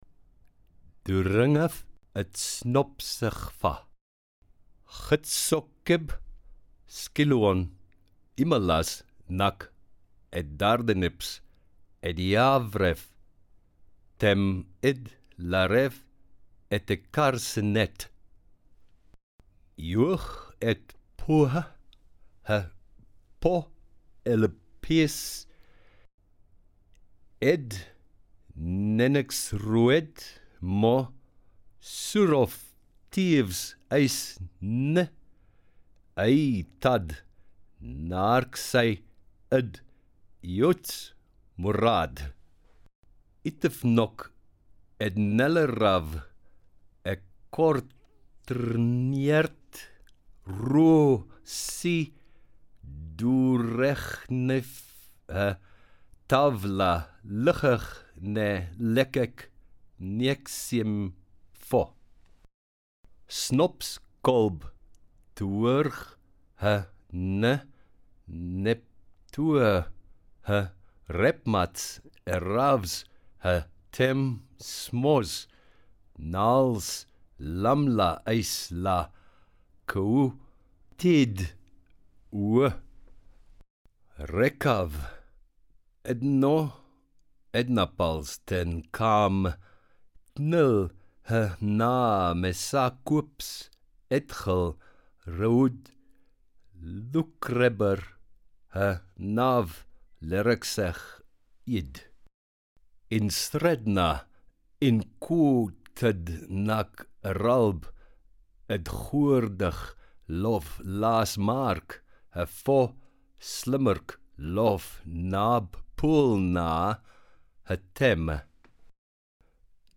6 3 Afgesponste Vngerhoed (normal read backwards).mp3